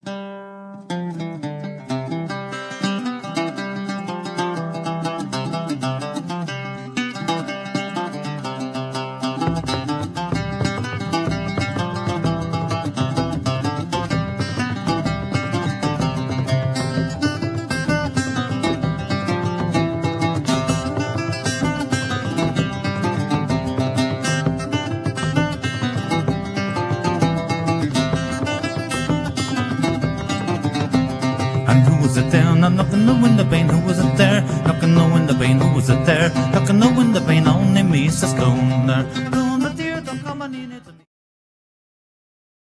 song-jig